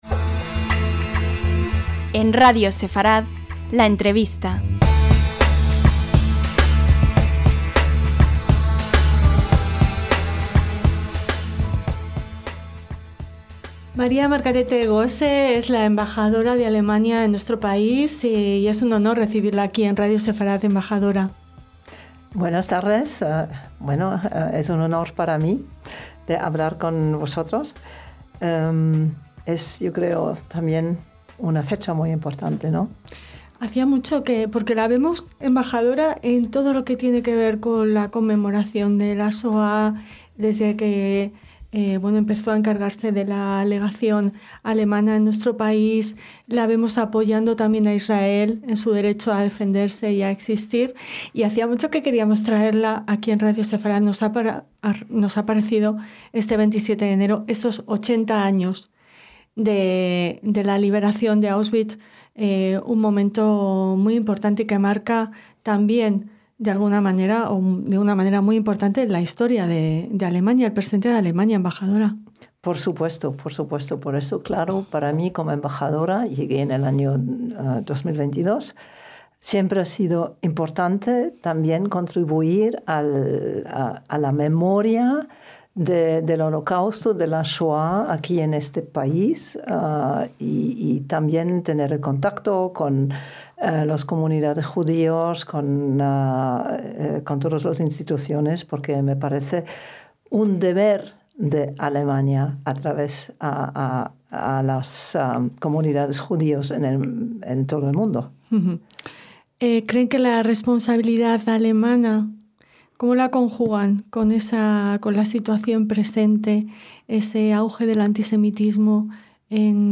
LA ENTREVISTA - La embajadora de Alemania en España, Maria Margarete Gosse, nos visita en Radio Sefarad a 80 años de la liberación del campo de concentración y exterminio Auschwitz-Birkenau. La embajadora nos habla de la especial responsabilidad de Alemania con la Memoria de la Shoá y cómo se combaten la negación y la banalización del Holocausto en su país.